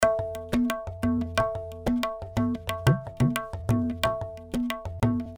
Tabla loops 90 bpm
indian tabla loops in 90 bpm, 32 loops in total
This is an Indian tabla drum loops (scale A), playing a variety of styles.
Played by a professional tabla player.
The tabla was recorded using one of the best microphone on the market, The AKG C-12 VR microphone. The loops are mono with no EQ, EFFECT or DYNAMICS, but exported stereo for easy Drop and play .